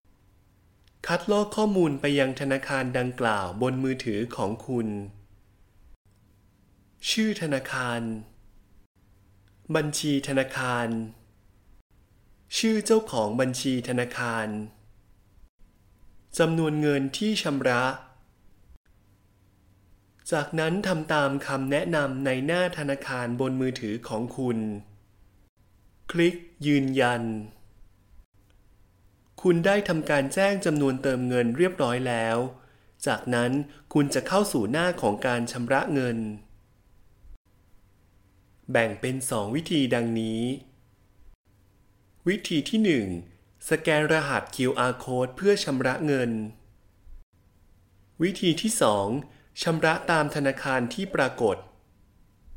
课件解说